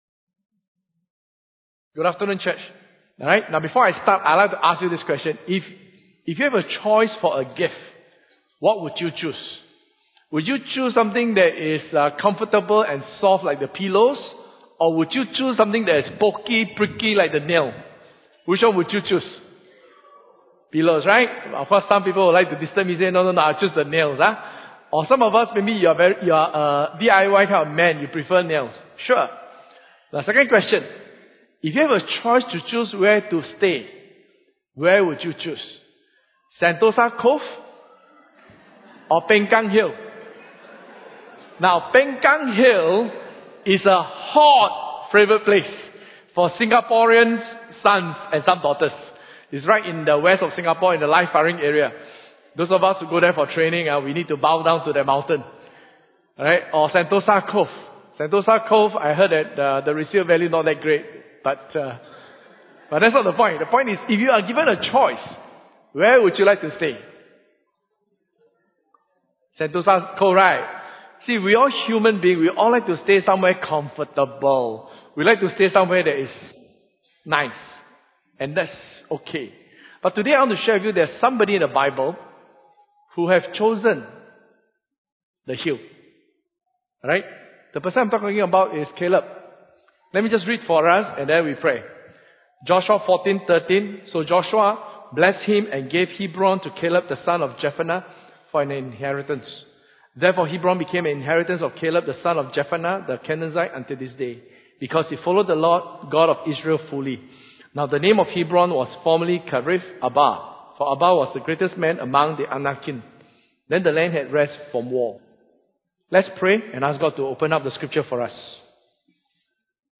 In this sermon, we dive into this conversation between Caleb and Joshua that helps us understand why Caleb was given this special privilege of claiming the land.